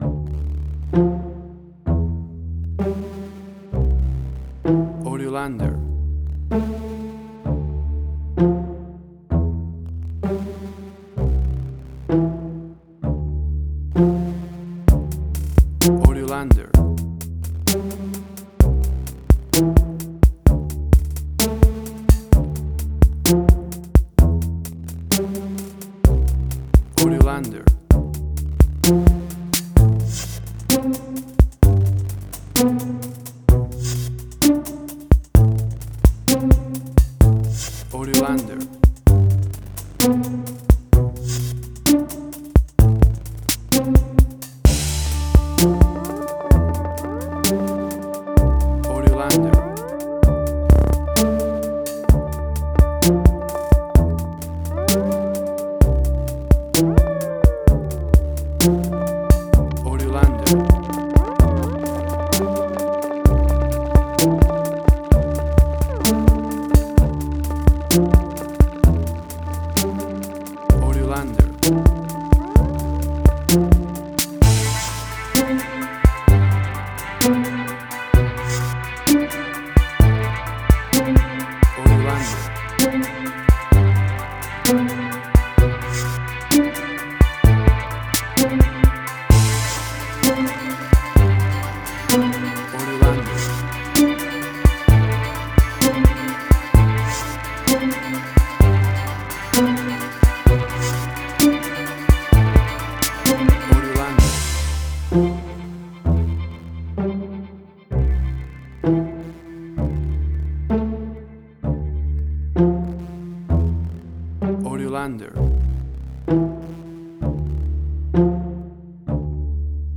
Steampunk Sci-fi.
Tempo (BPM): 64